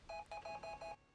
EpsonConfirm.ogg